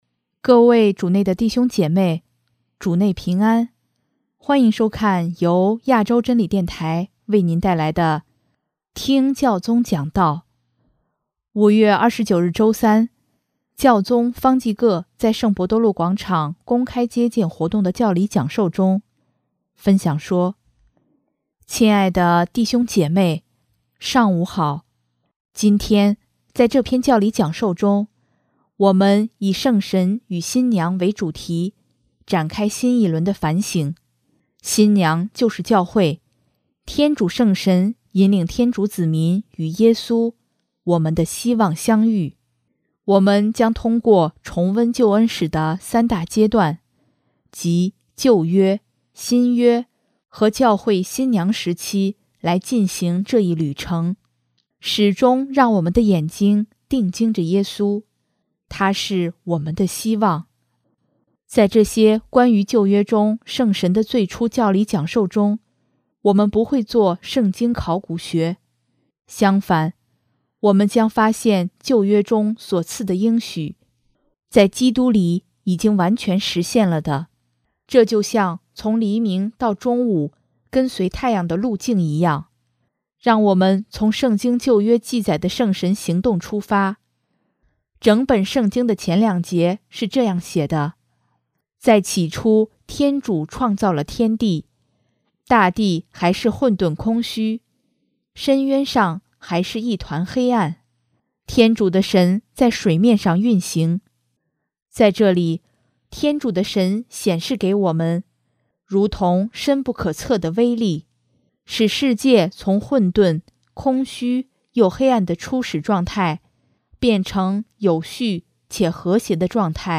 5月29日周三，教宗方济各在圣伯多禄广场公开接见活动的教理讲授中，分享说：